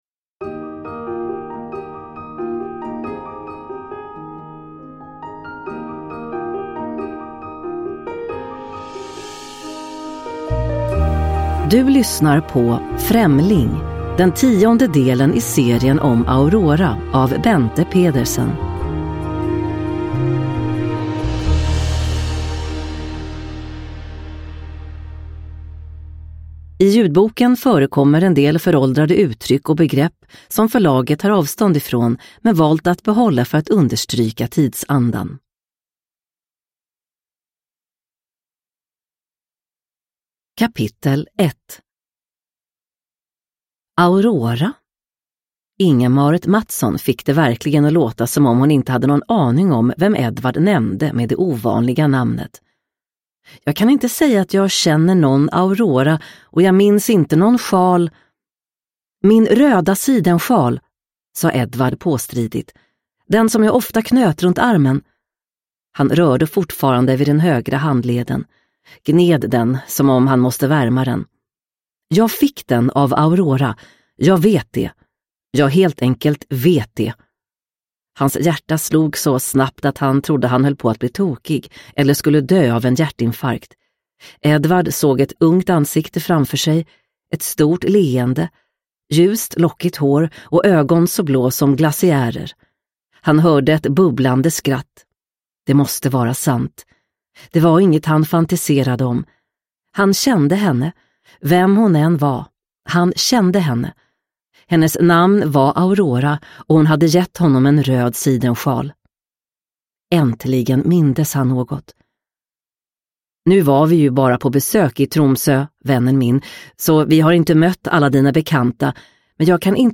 Främling – Ljudbok – Laddas ner